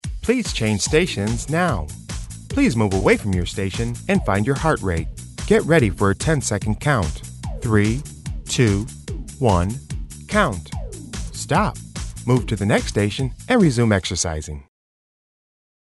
All our Cue CDs are studio recorded and work great on all 30 minute style fitness circuits.
Standard Cue: A basic Male or Female prompt to change stations.
The "previews" have music background for reference.
Std. Male Cue
CueCD-MaleCue.mp3